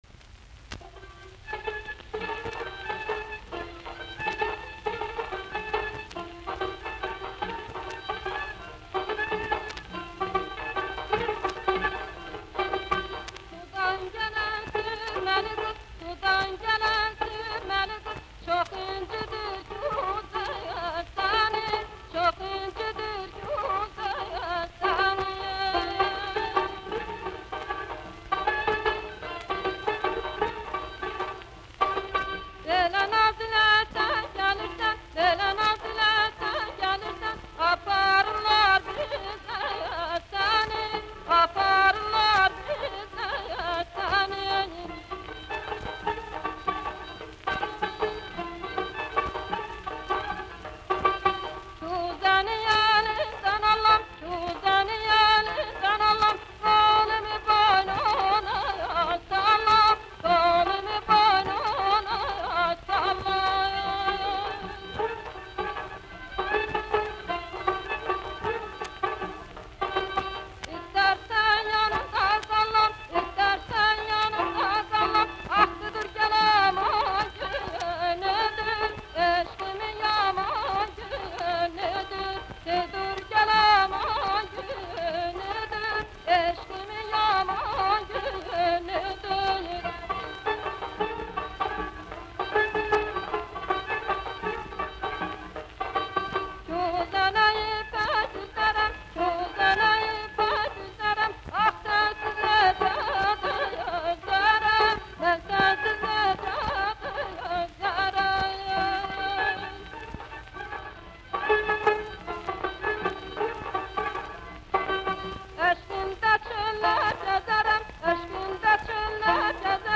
From Azerbaijan. Folk song, with instrumental trio.